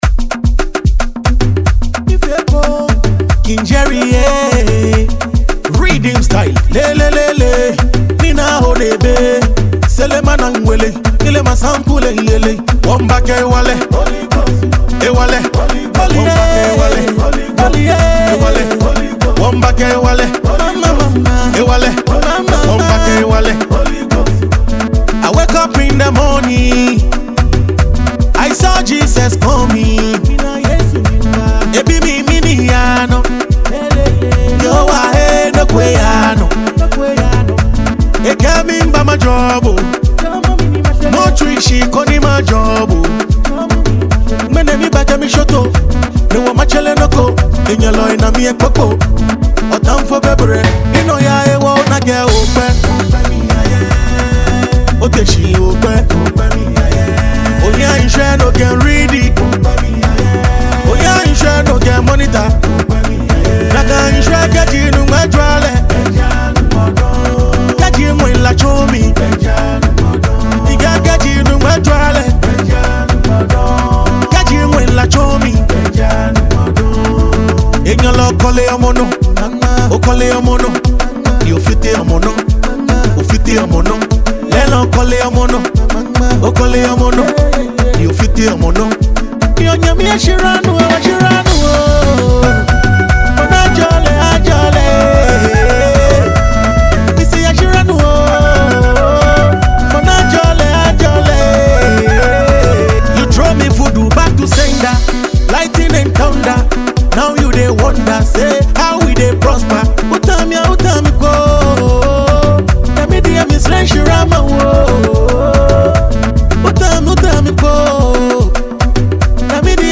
energetic dance song